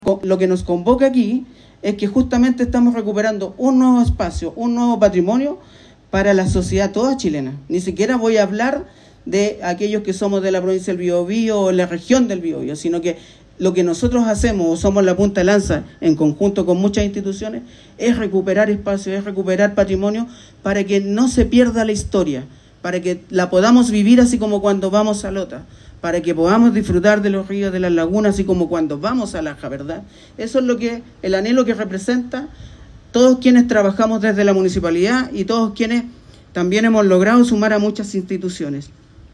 A su vez, el alcalde de San Rosendo, Rabindranath Acuña, se refirió a la relevancia de este hito para su comuna.